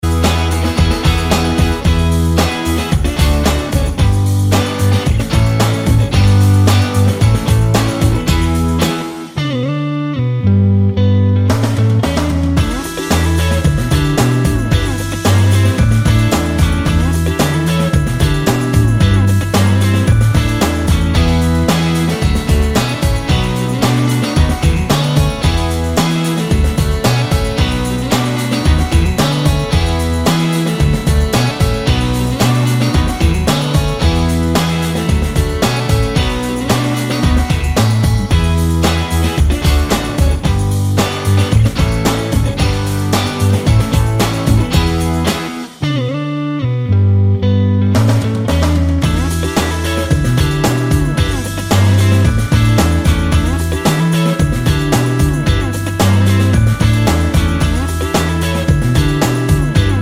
no Backing Vocals Indie / Alternative 3:13 Buy £1.50